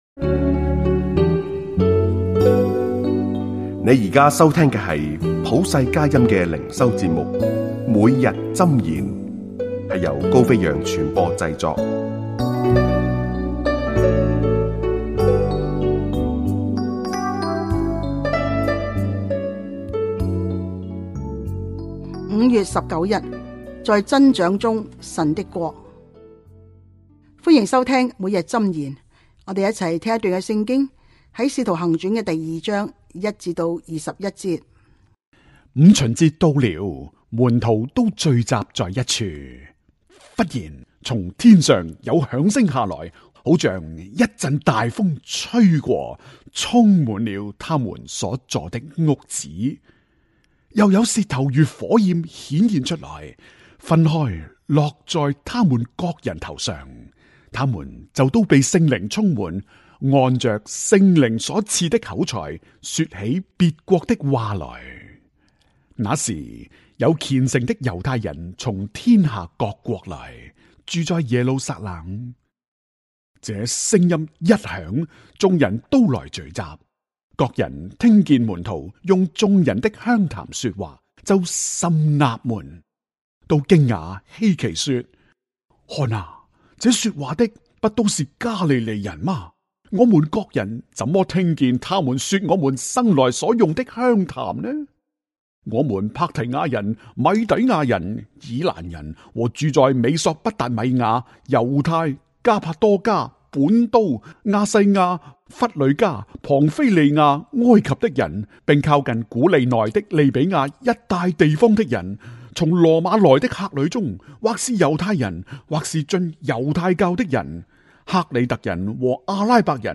粵語音頻下載